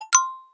unread-notification.041b9025.mp3